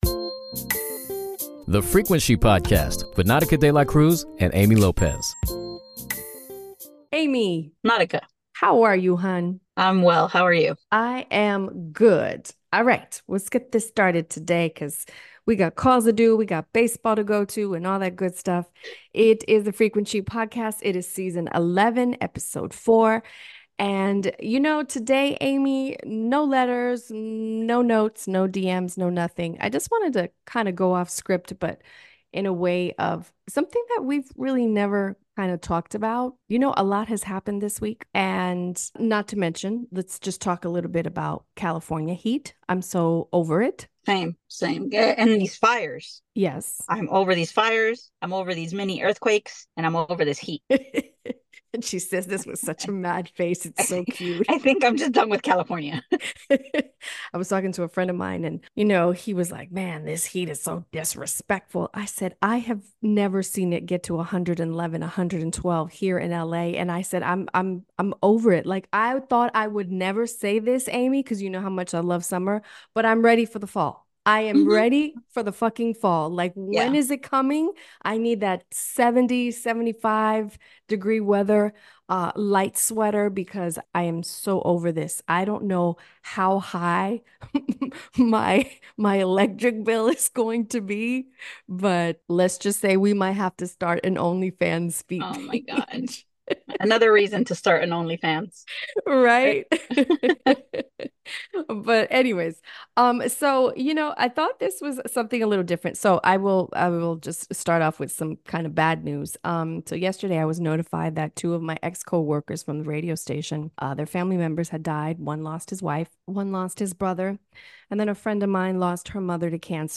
A candid conversation on how the fear of dying shapes our lives, decisions, and mental health. Is it possible to live fully while fearing death?